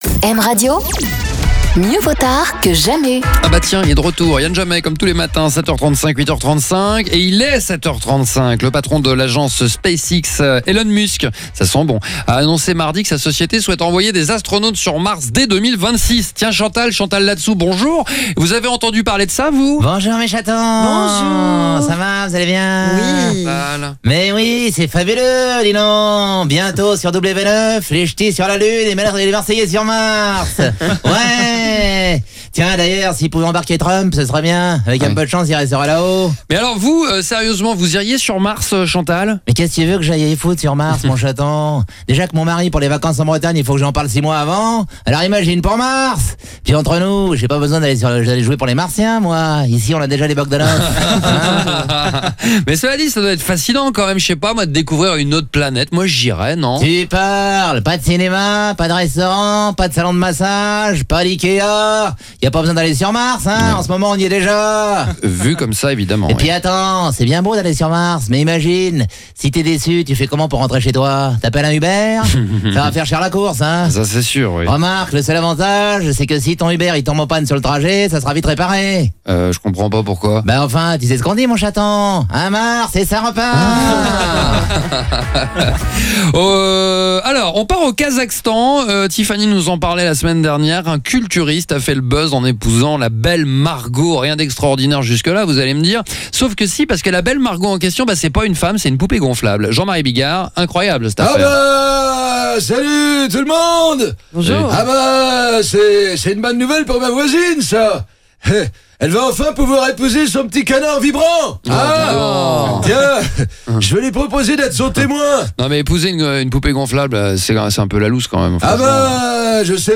refaire l’actualité avec ses imitations